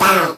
VEE Synth Voc 54.wav